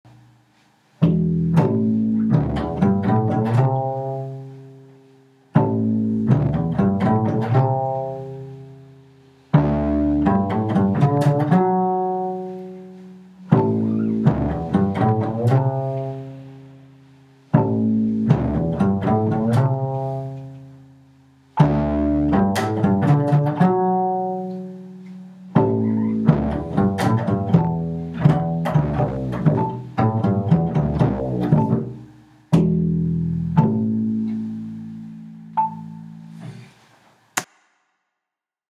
So hört sich das an, wenn ich mit Metronom übe: Dein Browser kann diesen Sound nicht abspielen. Klick auf der 1, einfach um zu üben weder zu treiben noch zu schleppen.